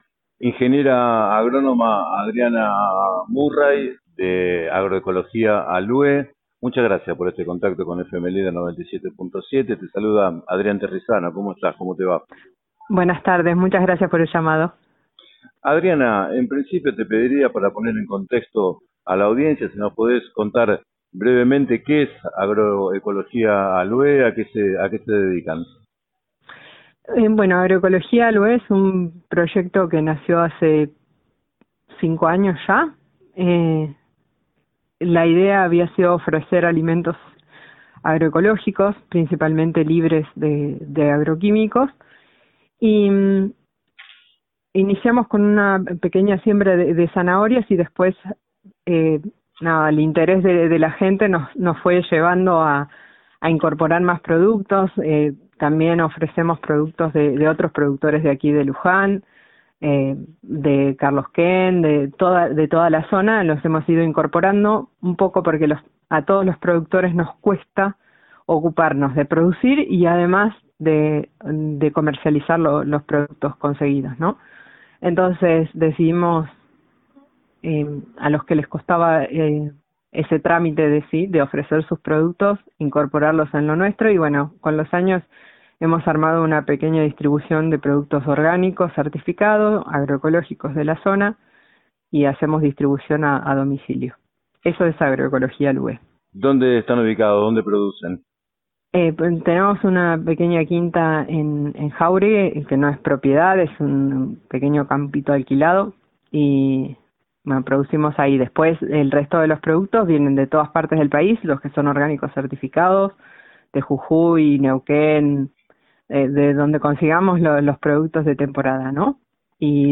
En declaraciones al programa 7 a 9 de FM Líder